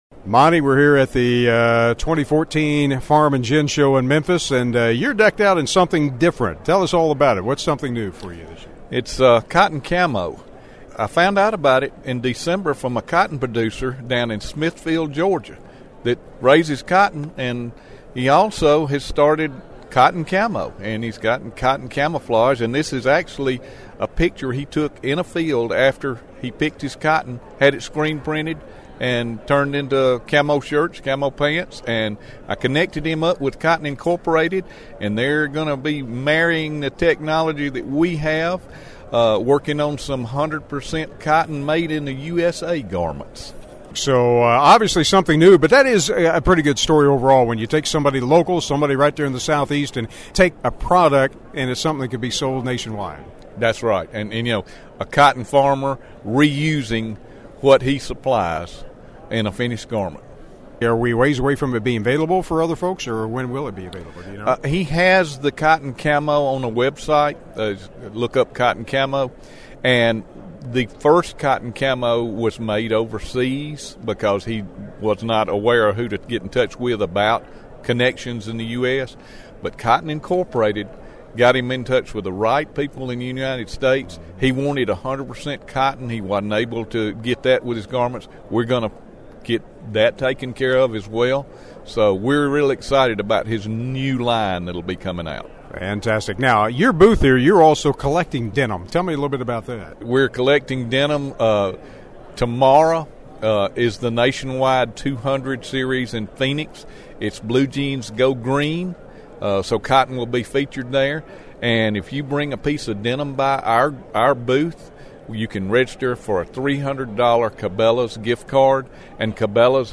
There’s a big crowd on hand for the first day of the 2014 Farm & Gin Show taking place here in Memphis, Tennessee and one of the more than 400 exhibitors is the Cotton Board.